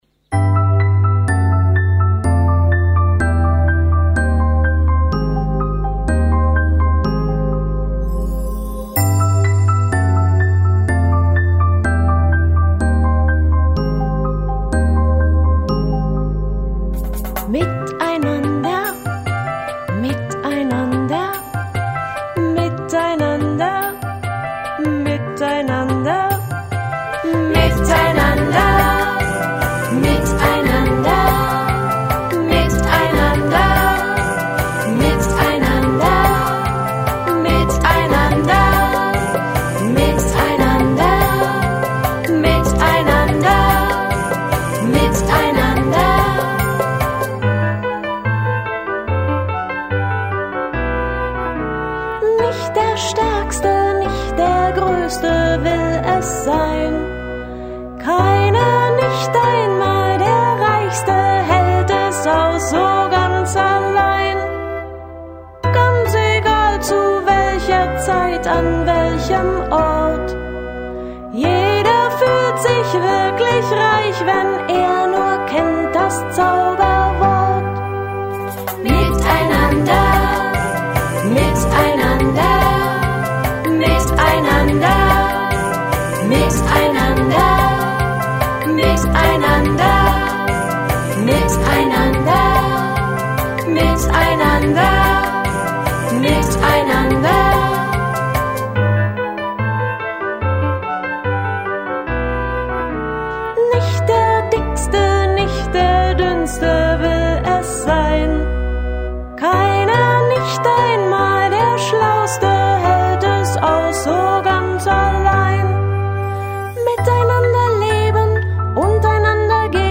Hörspiel für Kinder/Jugendliche